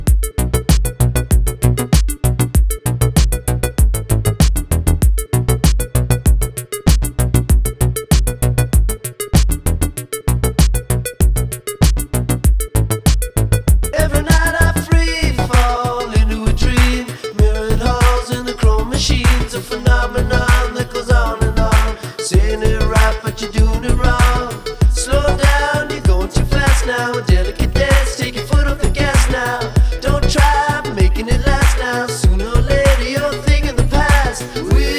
Alternative Rock American Trad Rock Indie Rock
Жанр: Рок / Альтернатива